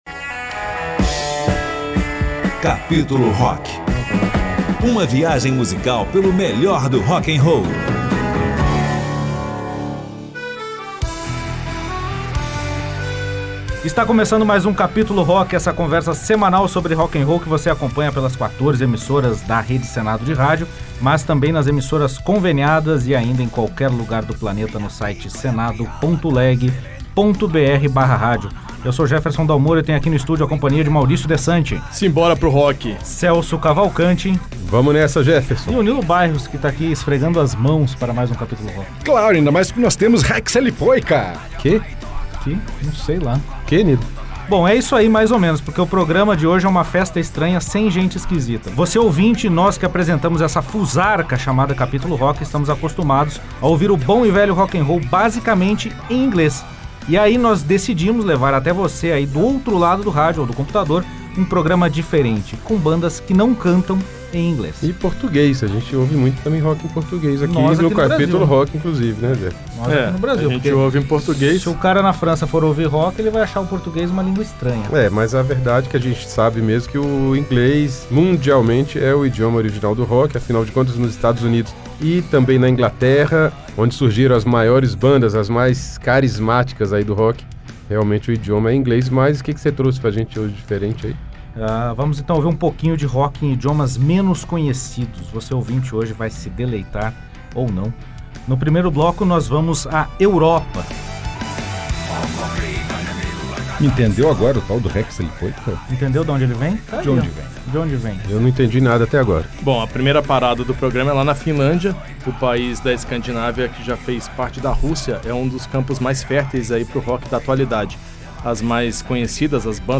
Vamos levar aos nossos ouvintes a sonoridade de bandas pouco conhecidas aqui no Brasil e que cantam em idiomas pouco usuais para nós brasileiros que curtimos o bom e velho rock n’roll.